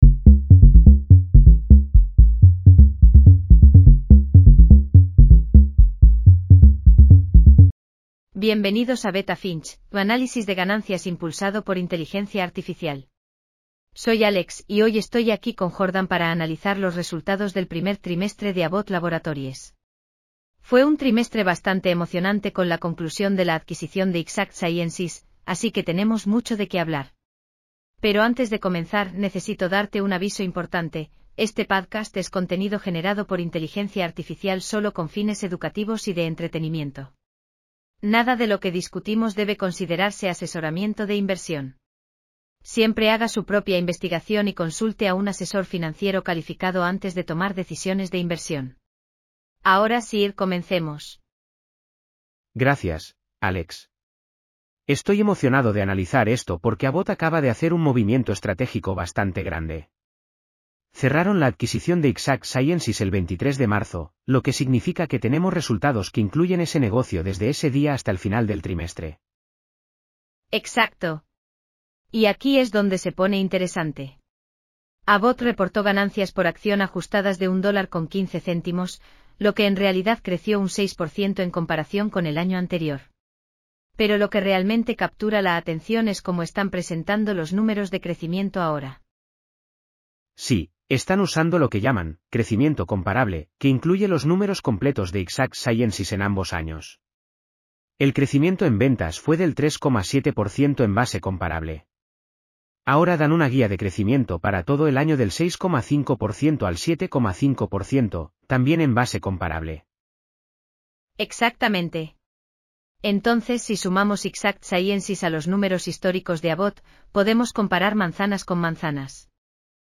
Bienvenidos a Beta Finch, tu análisis de ganancias impulsado por inteligencia artificial.